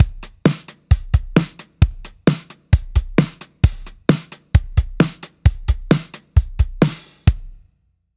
こんなふうに、1・3拍目にコンスタントにキックが鳴る状態が、拍子の強拍の位置をていねいに伝えてあげているわけですから、もっとも安定的な構造といえます。
r1-synco-drum-0.mp3